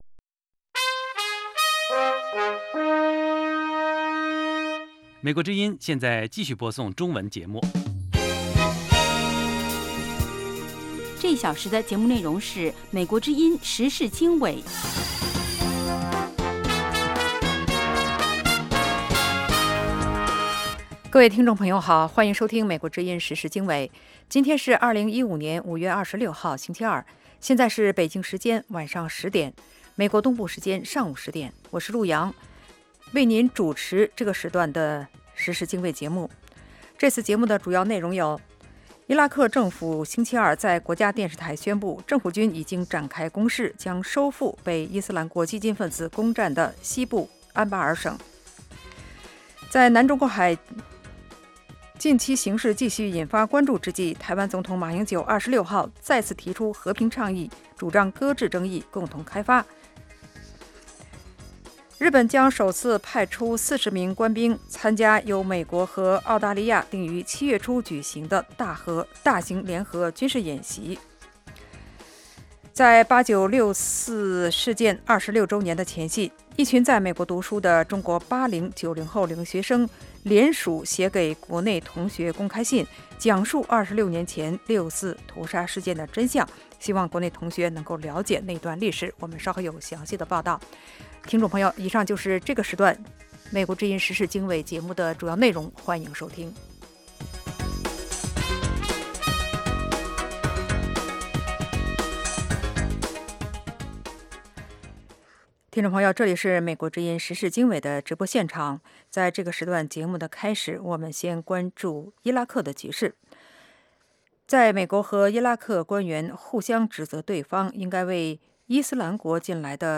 北京时间晚上10-11点广播节目